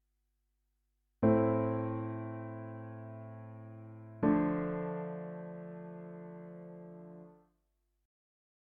Here the dominant chord is minor, which uses the subtone.
Example 13: v-i Cadence